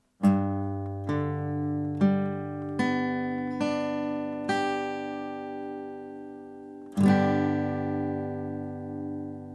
G-Dur (Barré, E-Saite)
G-Dur-Barre-E.mp3